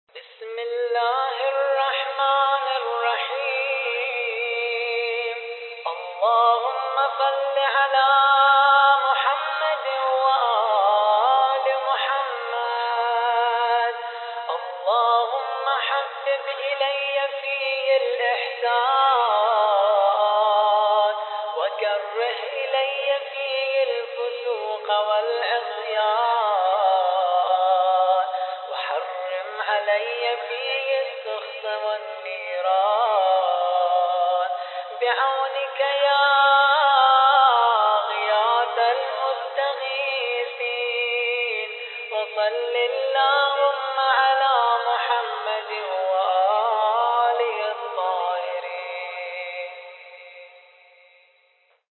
الخطیب: الرادود